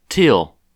Captions English Audio pronunciation of the surname "Thiel" in U.S. English (Midwestern accent).
En-us-Thiel.ogg